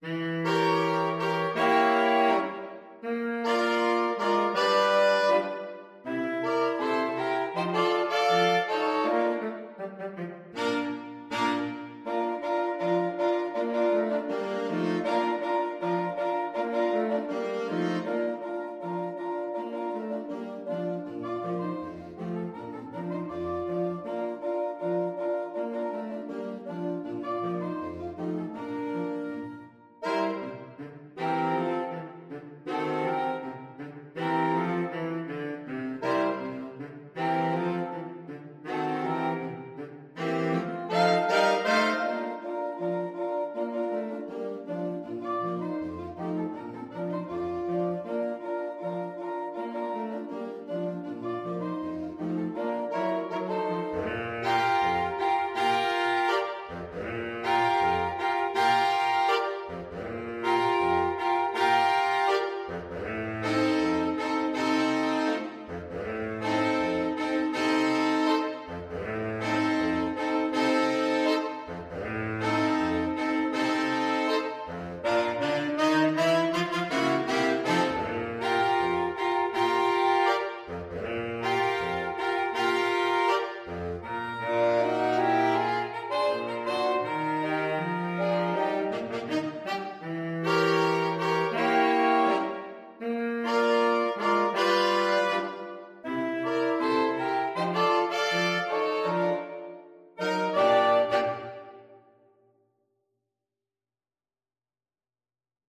Allegro Moderato = c. 80 (View more music marked Allegro)
2/2 (View more 2/2 Music)
Jazz (View more Jazz Saxophone Quartet Music)